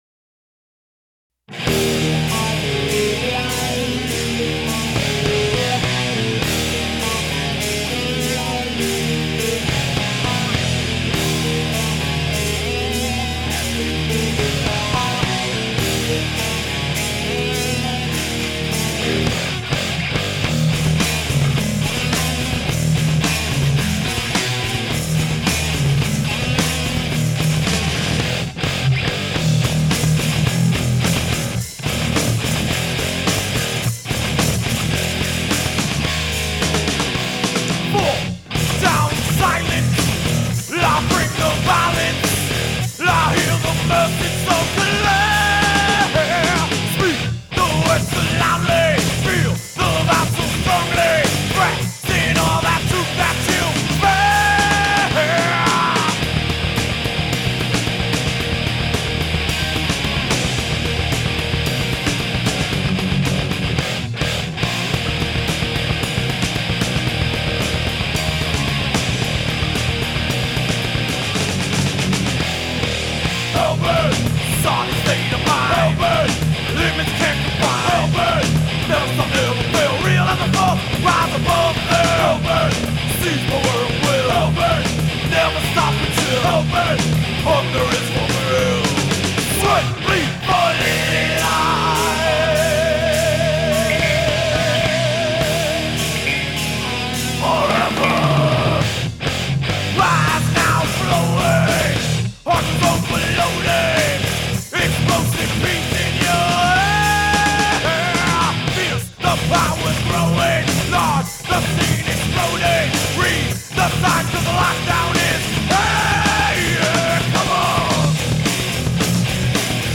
Bass
Alien Flyers Studios, New York, NY